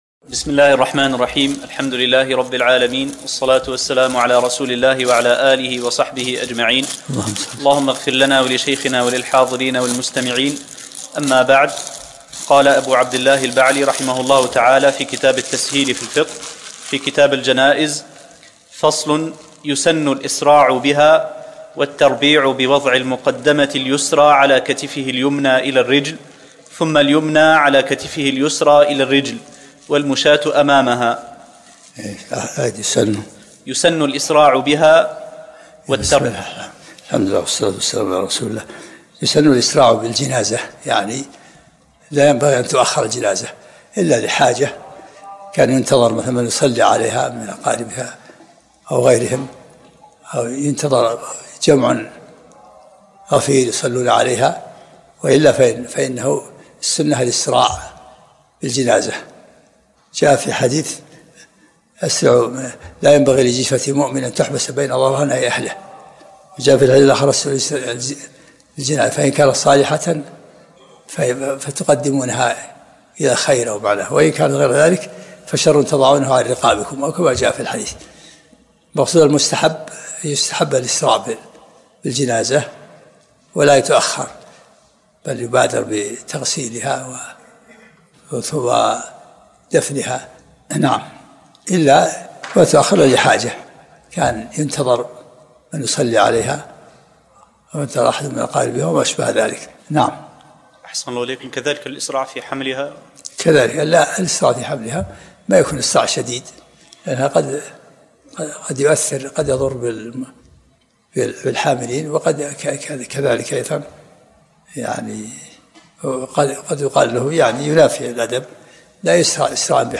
الدروس العلمية